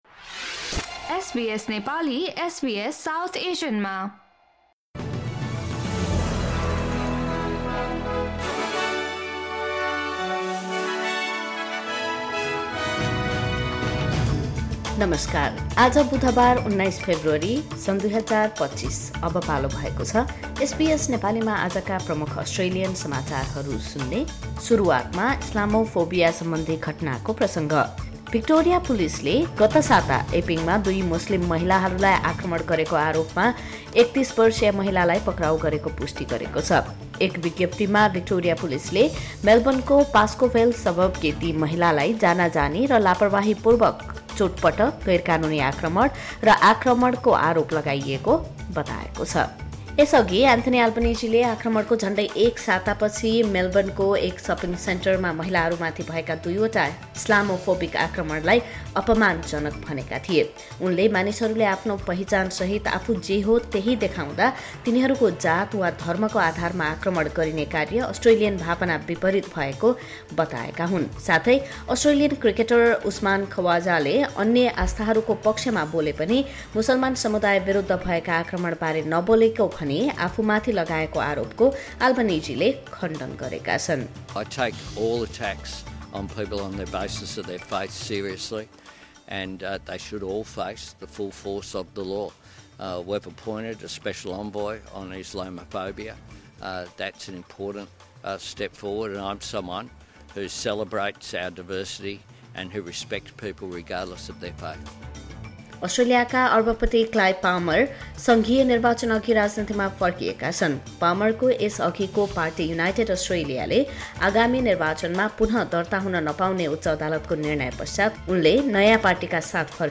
SBS Nepali Australian News Headlines: Tuesday, 18 February 2025